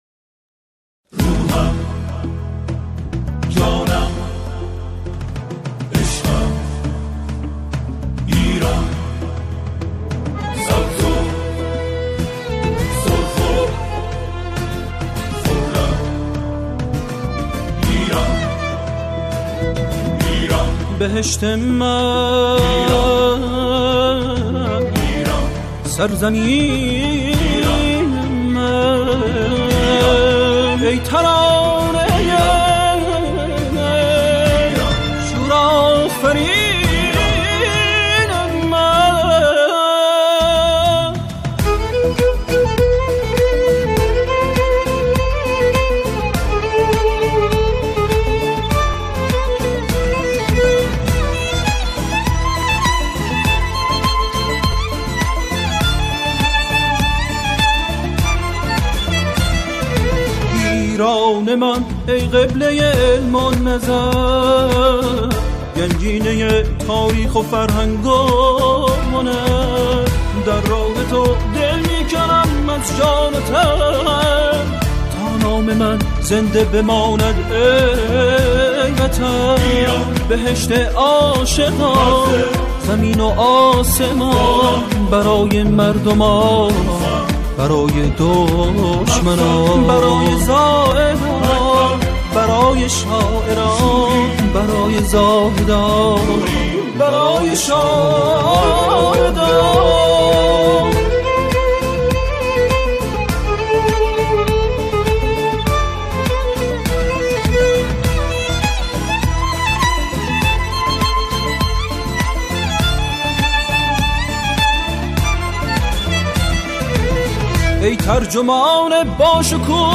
گروهی از جمعخوانان اجرا می‌کنند